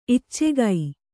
♪ iccegai